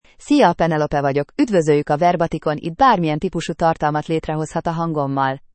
PenelopeFemale Hungarian AI voice
Penelope is a female AI voice for Hungarian (Hungary).
Voice sample
Listen to Penelope's female Hungarian voice.
Female
Penelope delivers clear pronunciation with authentic Hungary Hungarian intonation, making your content sound professionally produced.